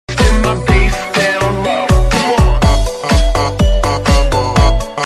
can bang sound effects free download